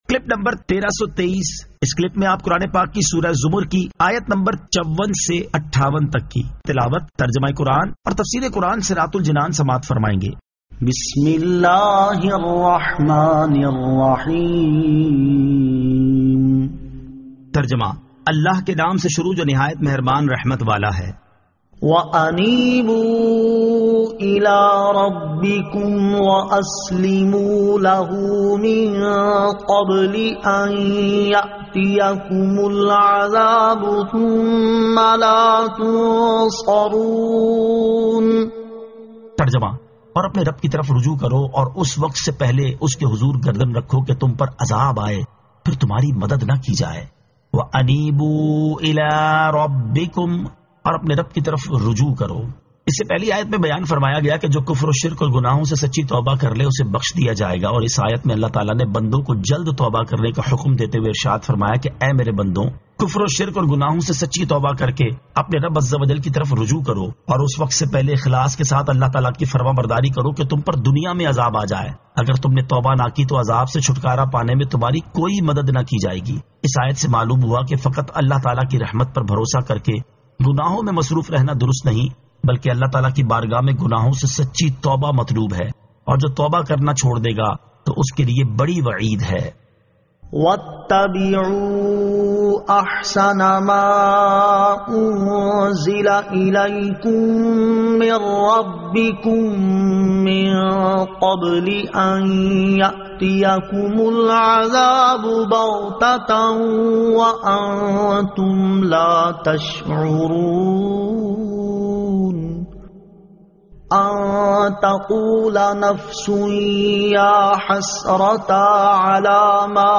Surah Az-Zamar 54 To 58 Tilawat , Tarjama , Tafseer